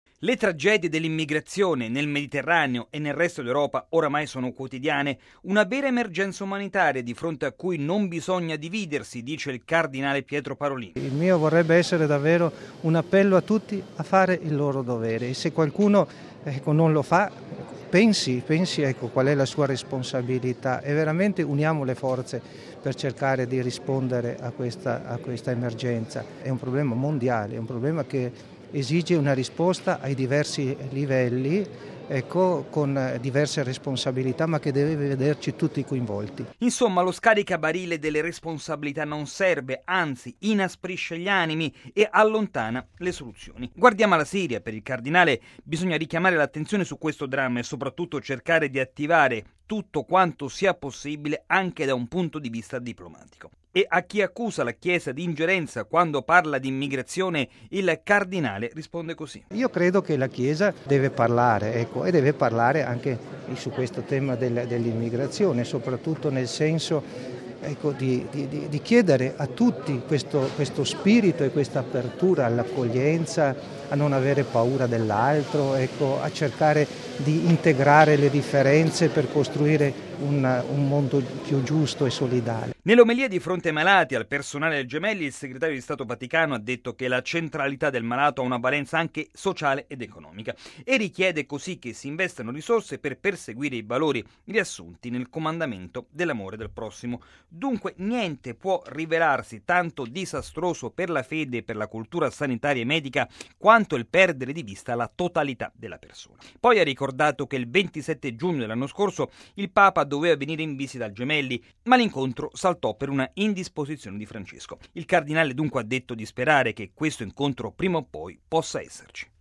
Lo ha detto il cardinale segretario di Stato Pietro Parolin, a margine di una Messa al Policlinico Gemelli di Roma.